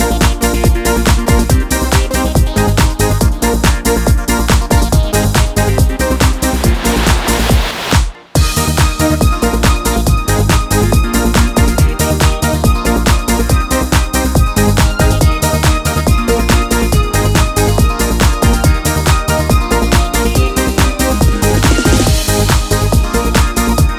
For Solo female Duets 3:20 Buy £1.50